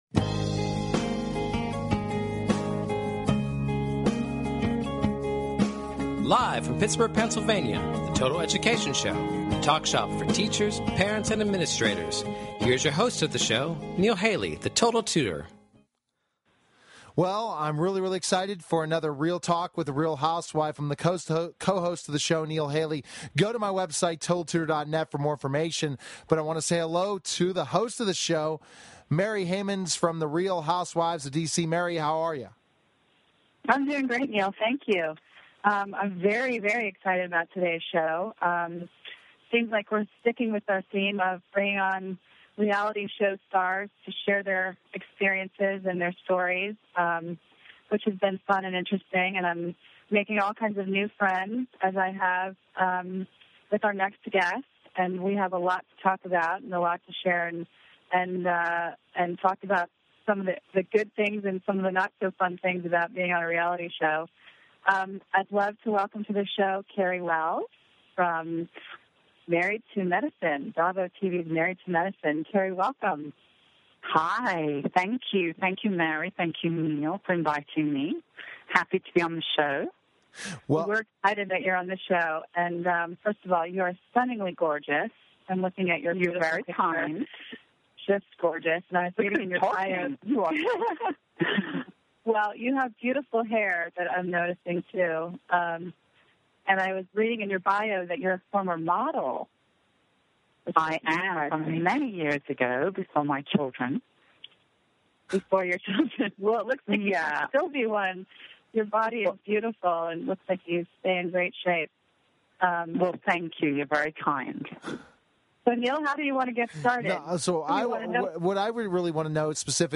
Total Education Celebrity Show {also known as "The Total Education Hour" is an educational talk show that focuses on the listeners' needs. Catch weekly discussions focusing on current education news at a local and national scale.